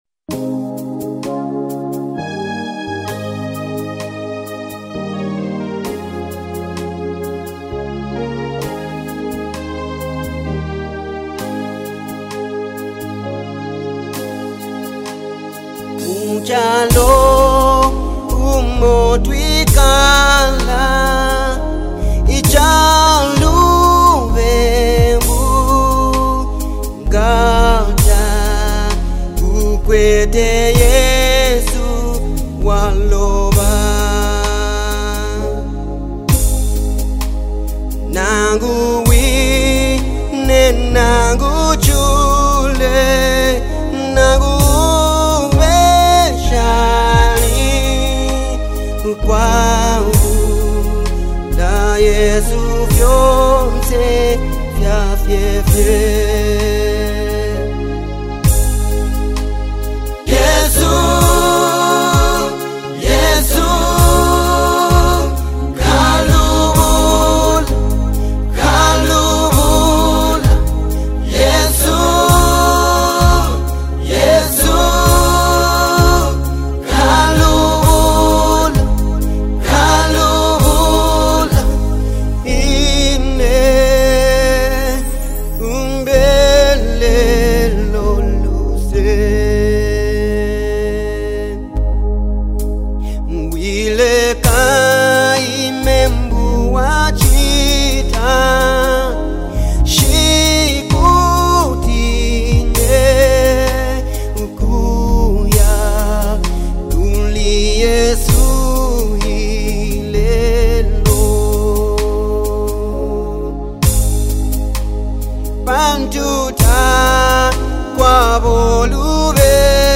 Zambia’s award-winning gospel minister
powerful vocals and spiritual depth
passionate delivery